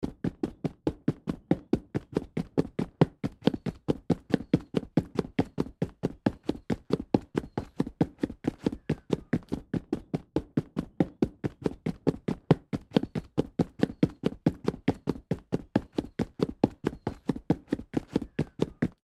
Звуки сапог
Бег в армейских ботинках